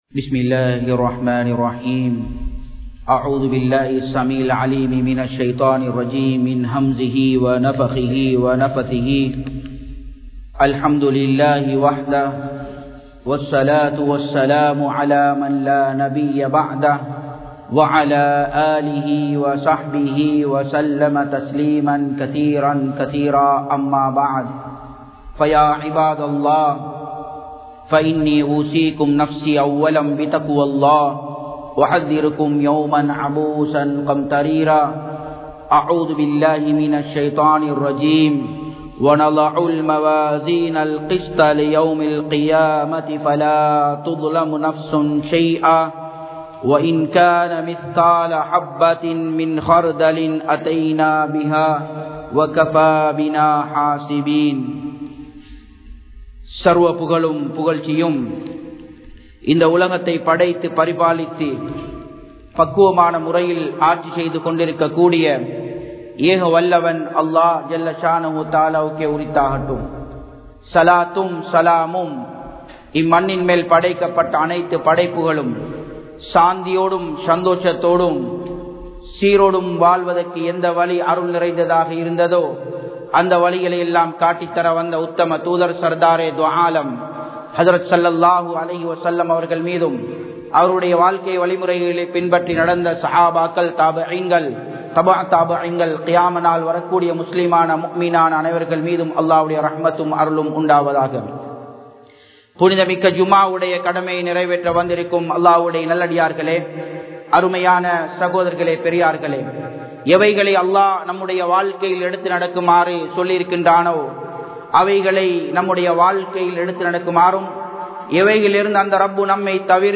Aniyaayaththin Vilaivuhal (அநியாயத்தின் விளைவுகள்) | Audio Bayans | All Ceylon Muslim Youth Community | Addalaichenai
Japan, Nagoya Port Jumua Masjidh 2017-09-22 Tamil Download